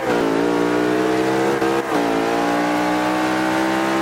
Index of /server/sound/vehicles/lwcars/merc_slk55